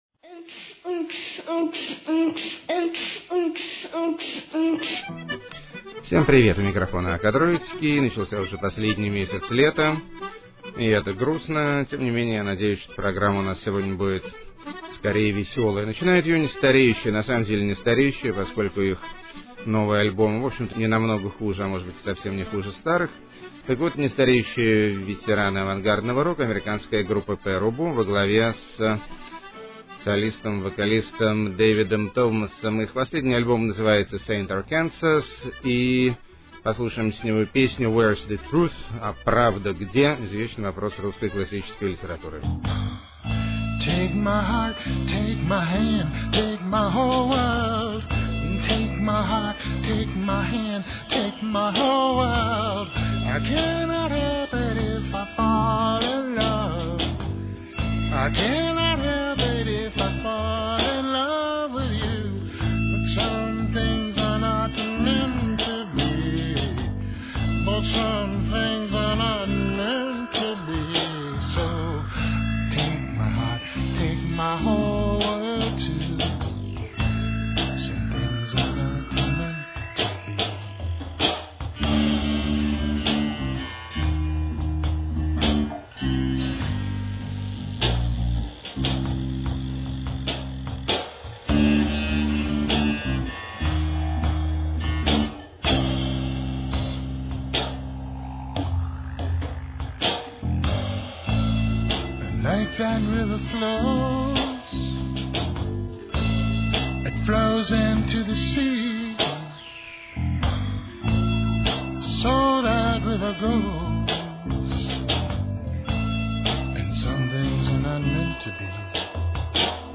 Интелло-поп Высшей Пробы.
Детский Хор, Записи 1973 – 1974 Г.г.] 6.
Китчевый Электро-рок.
Смешно Поют.] 10.